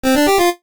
jingles-retro_07.ogg